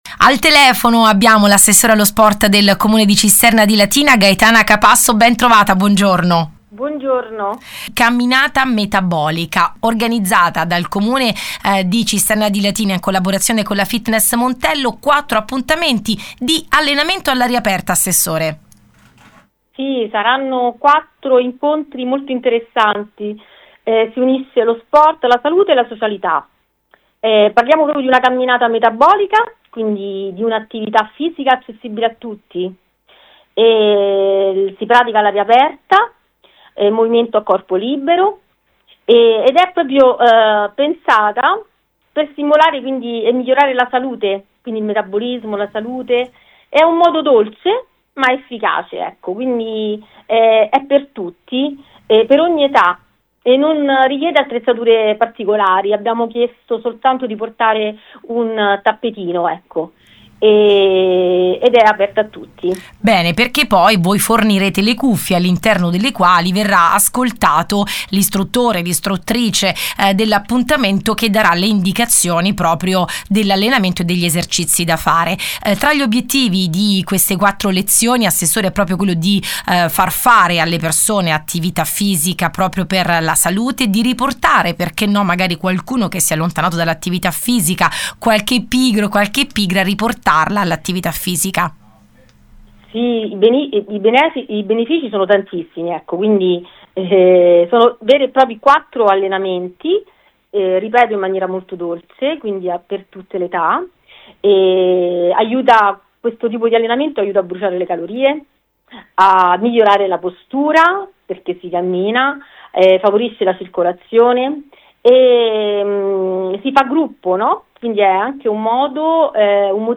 Ai nostri microfoni l’Assessore allo sport del comune di Cisterna di Latina Gaetana Capasso.